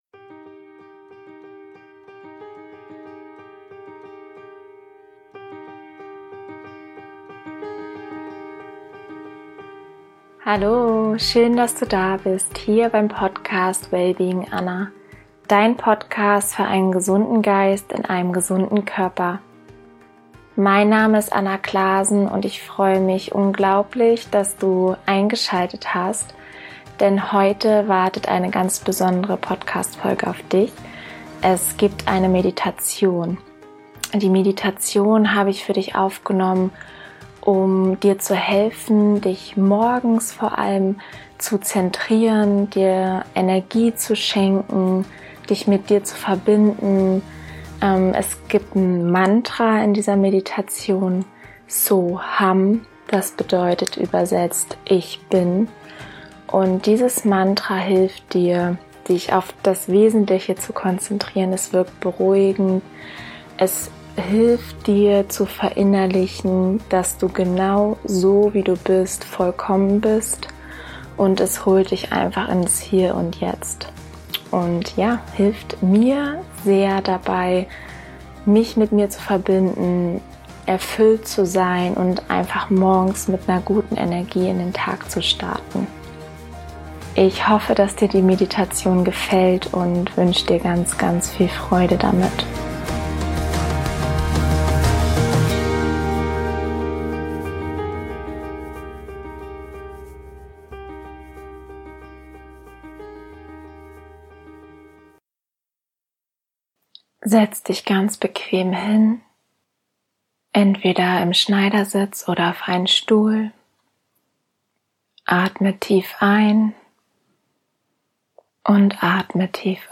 Meditation für innere Ruhe und Gelassenheit